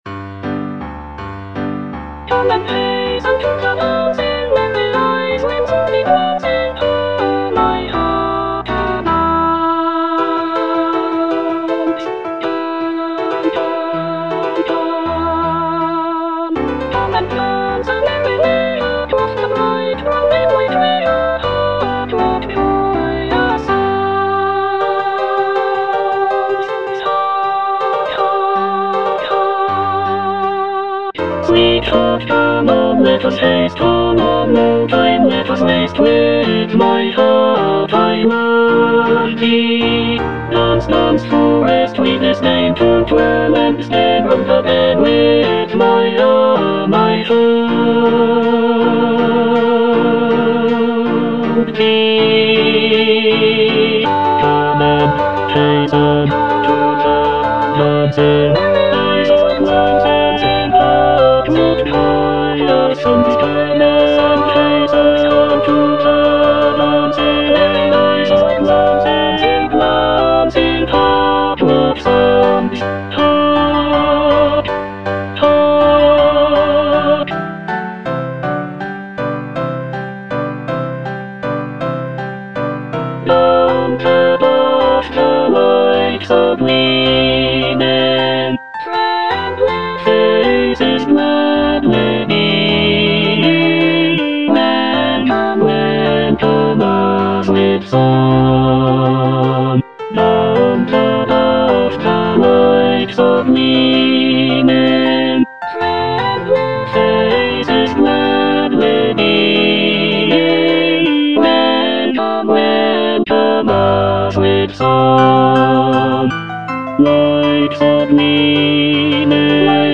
(tenor I) (Emphasised voice and other voices) Ads stop